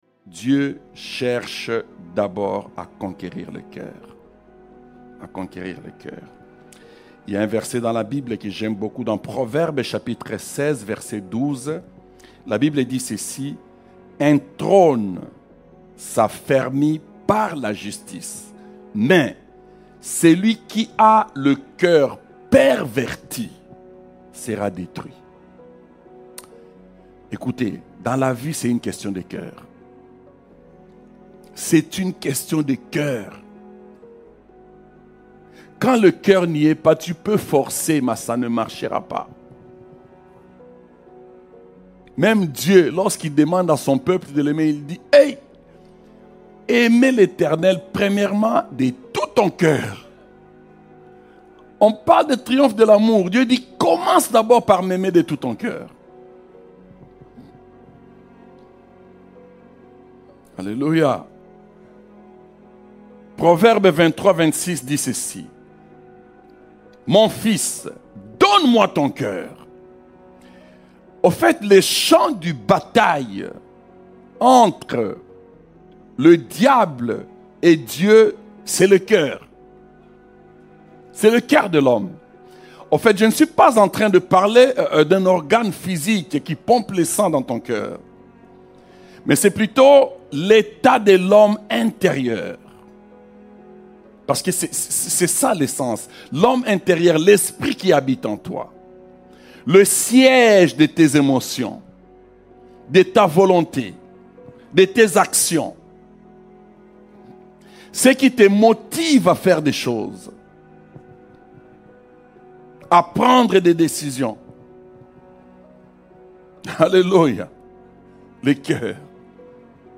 sermon1-ACVM-Francais-audio-.mp3